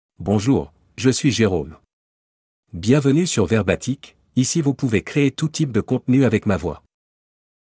MaleFrench (France)
JeromeMale French AI voice
Jerome is a male AI voice for French (France).
Voice sample
Listen to Jerome's male French voice.
Jerome delivers clear pronunciation with authentic France French intonation, making your content sound professionally produced.